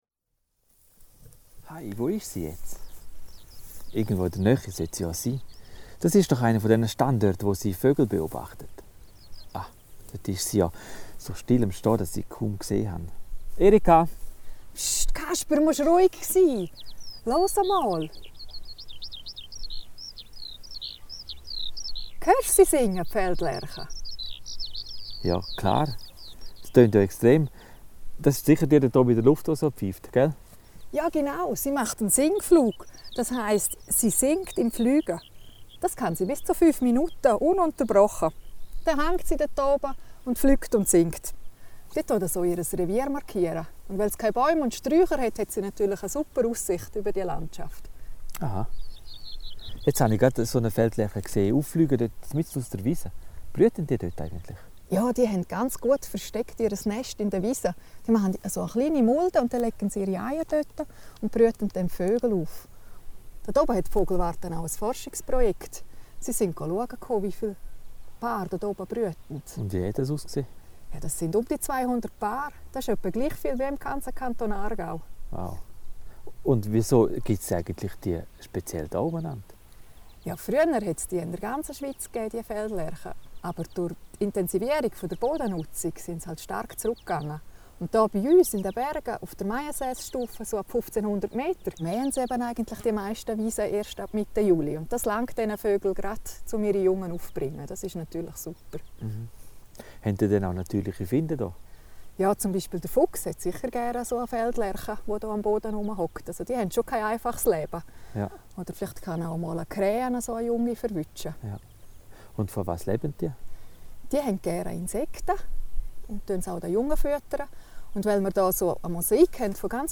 Hörgeschichten mia natira | Naturpark Beverin
||Wiesen und Weiden werden von unterschiedlichen Personen genutzt. In den Hörgeschichten erzählen zehn Personen was sie mit Wiesen und Weiden zu tun haben und gewähren so einen Einblick in ihre persönlichen Perspektiven auf die Thematik Artenvielfalt und Bergkulturlandschaft.